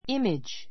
image A2 ímidʒ イ メ ヂ （ ⦣ × イ メ ー ヂ ではない） 名詞 ❶ 像 an image of Buddha an image of Buddha 仏の像 ❷ 心に浮 う かぶ像, イメージ I still have a clear image of her.